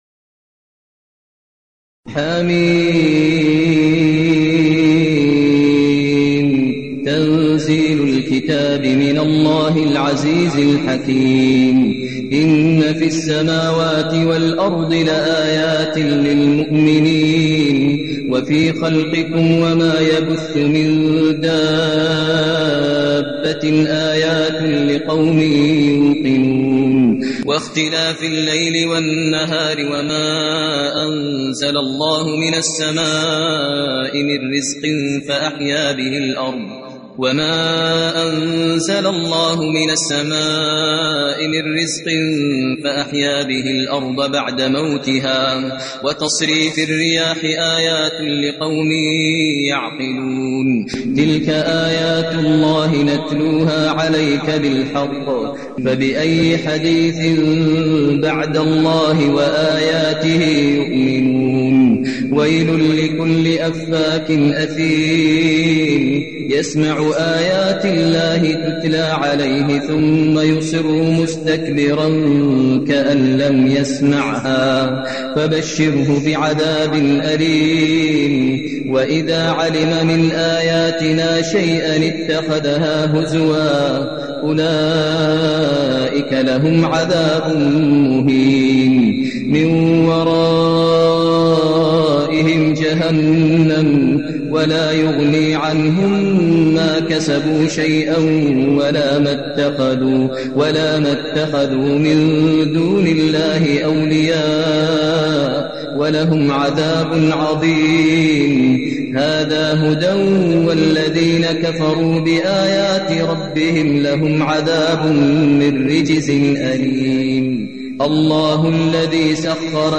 المكان: المسجد النبوي الشيخ: فضيلة الشيخ ماهر المعيقلي فضيلة الشيخ ماهر المعيقلي الجاثية